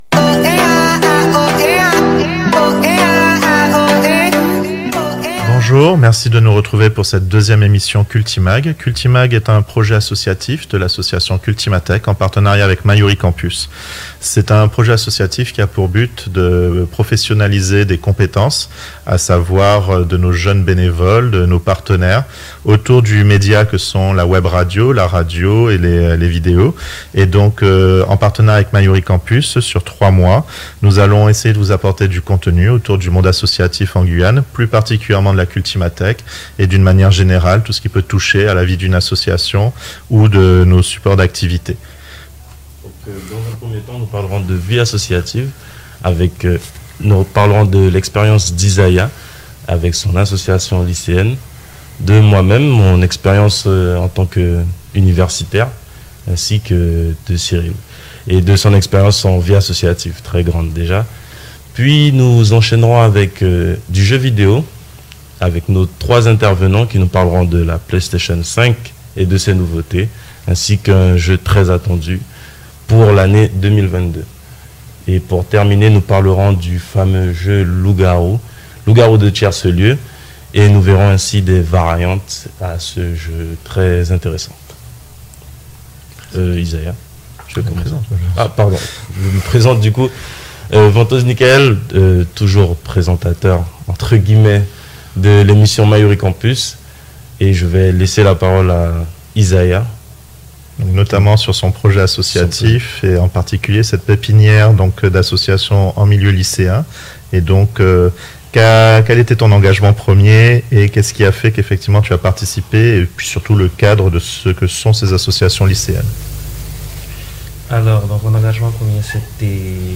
Des jeunes bénévoles parlent de leur passion pour les jeux vidéos
Cultimag, dans ce deuxième numéro, nous fait plonger les pieds joints dans l'univers des jeux vidéos. Entrecoupé des témoignages de jeunes, sur leurs premiers pas, effectués dans le milieu associatif guyanais. Cette émission est diffusée en radio, chaque mercredi à partir de 16h10, sur 107.6 à Cayenne et 89.2 à Saint-Laurent du maroni.